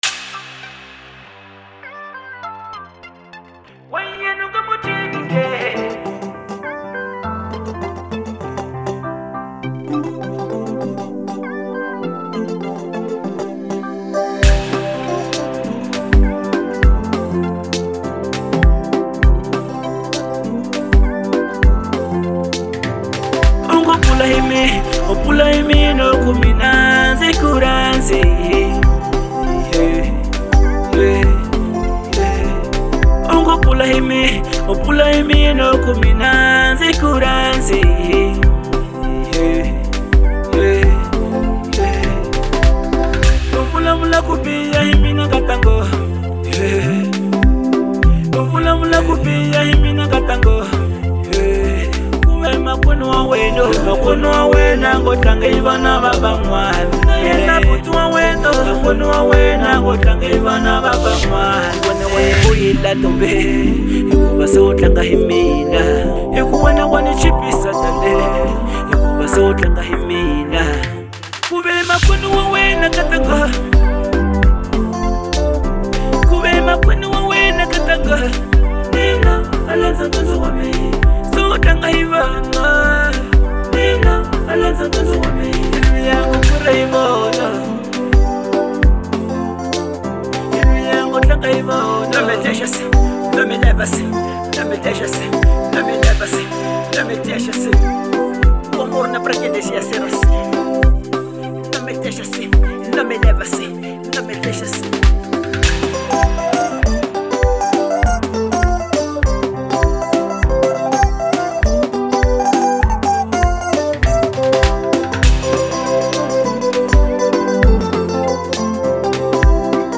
| Afro Bongo